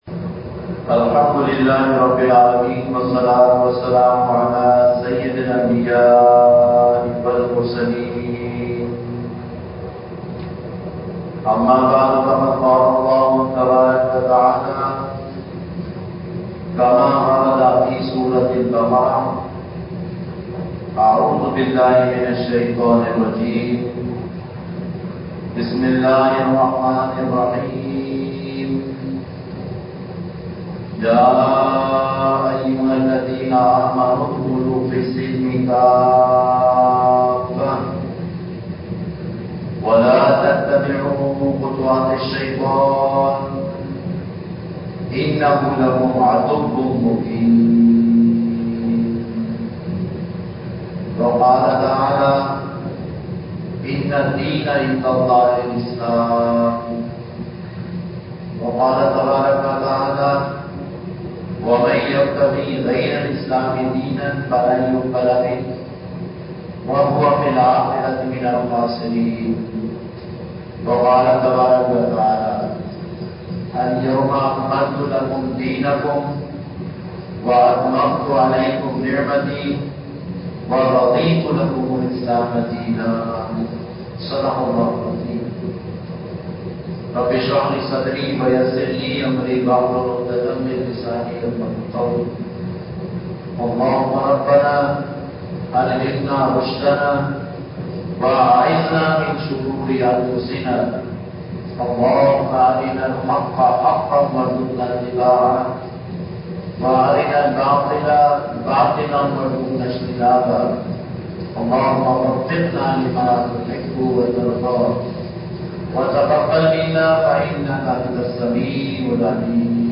Khutbat-e-Jummah (Friday Sermons)
Masjid Al-Furqan, Karachi University Society, Gulzar-e-Hijri, Karachi, Pakistan